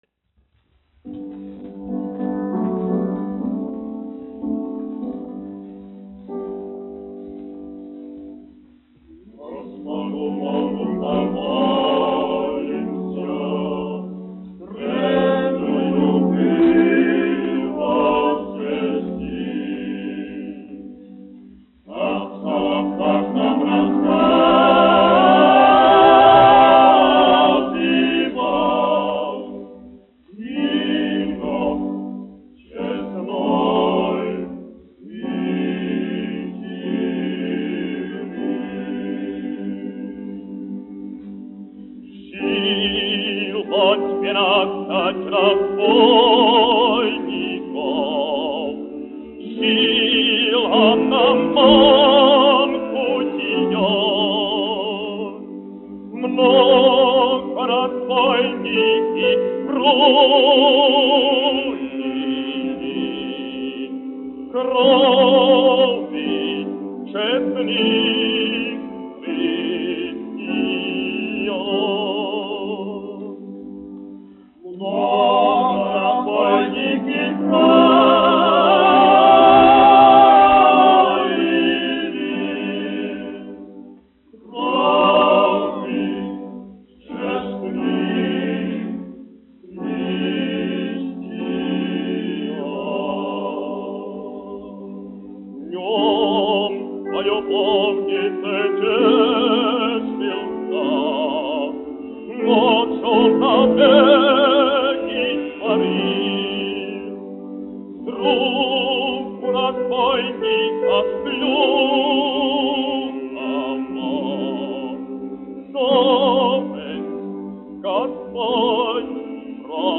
1 skpl. : analogs, 78 apgr/min, mono ; 25 cm
Krievu tautasdziesmas
Vokālie seksteti
Skaņuplate
Latvijas vēsturiskie šellaka skaņuplašu ieraksti (Kolekcija)